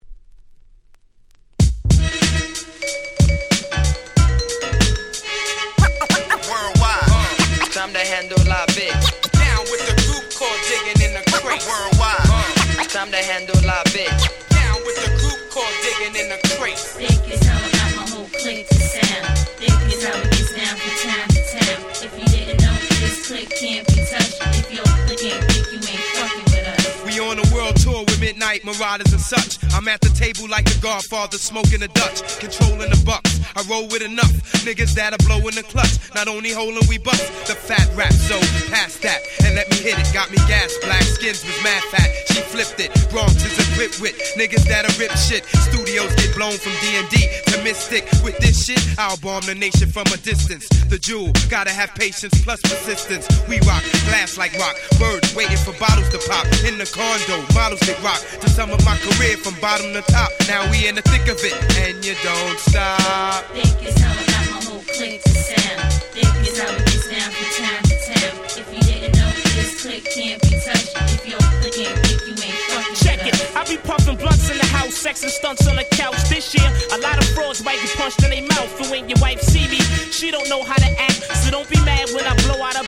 99’ Smash Hit Hip Hop !!
Boom Bap